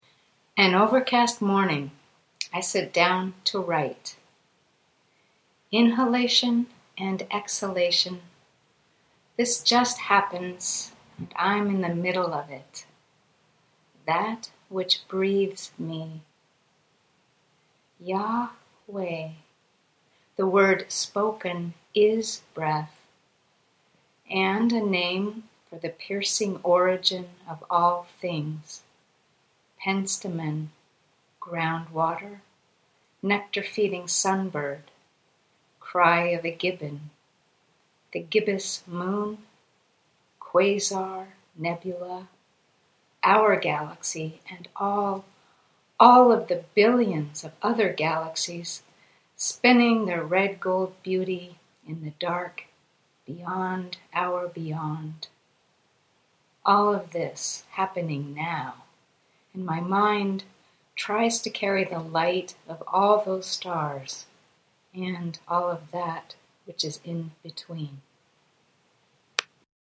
Poem